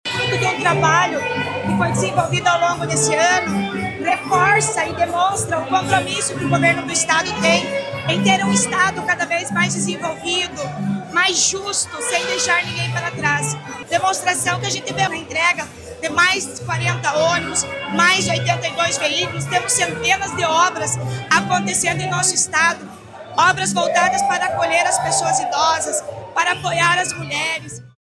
Sonora da secretária da Mulher, Igualdade Racial e Pessoa Idosa, Leandre Dal Ponte, sobre a entrega de 40 ônibus e 82 carros para reforçar cuidado às mulheres e pessoas idosas